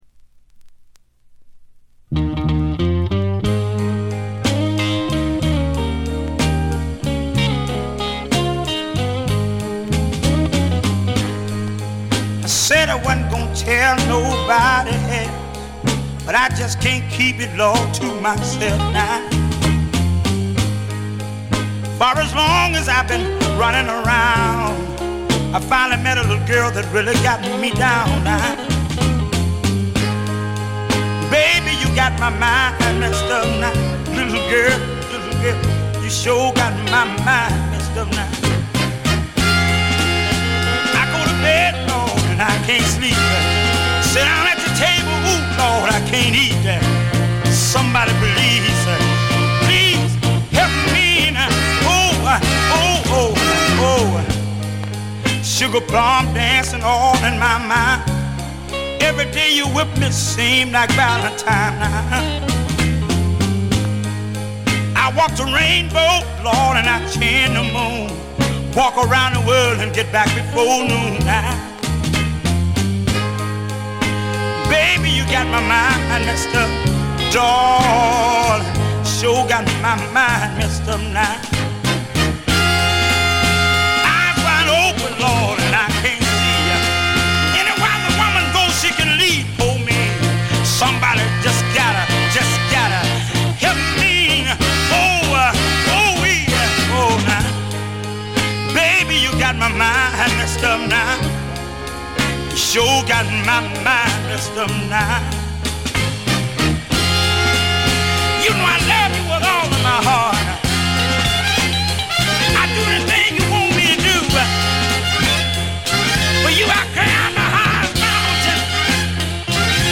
モノラル・プレス。
試聴曲は現品からの取り込み音源です。